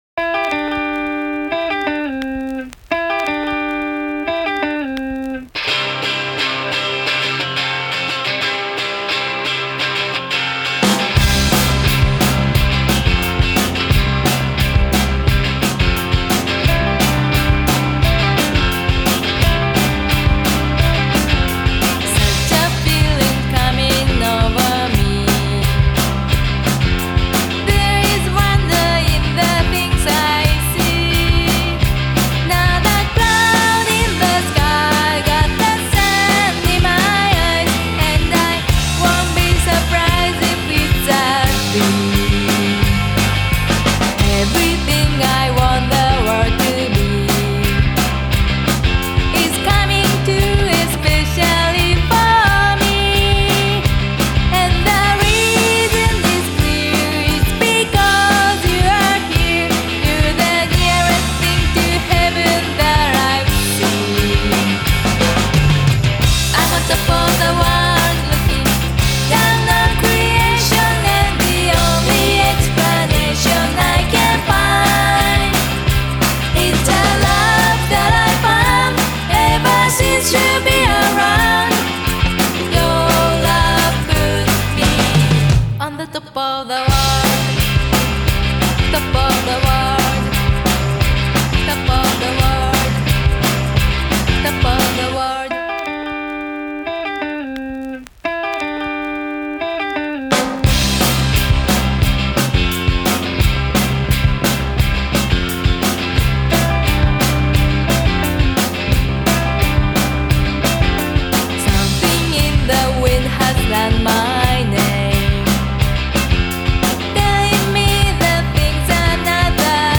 unconventional covers